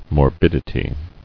[mor·bid·i·ty]